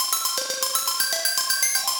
SaS_Arp01_120-C.wav